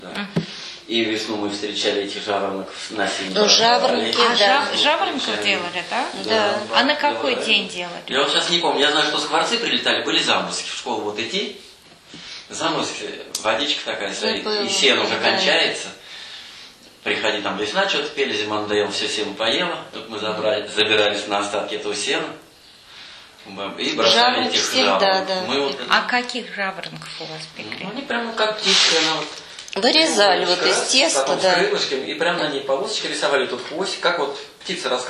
Рассказ